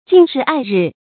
敬时爱日 jìng shí ài rì
敬时爱日发音
成语注音 ㄐㄧㄥˋ ㄕㄧˊ ㄞˋ ㄖㄧˋ